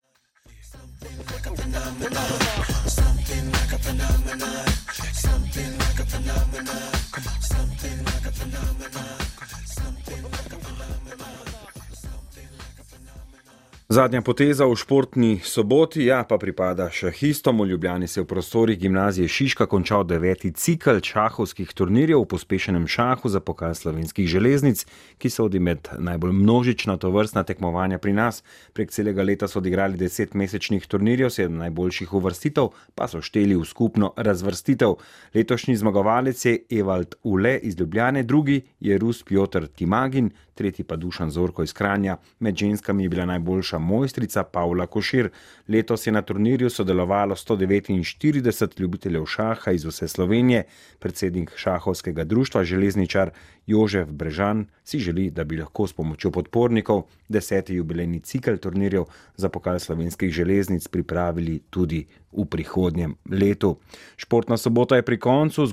Ob poslušanju prispevka Val-a 202 o šahovskem ciklu 2013, ki se ga je udeležilo 175 igralcev, si lahko ogledate nekaj utrinkov s turnirjev.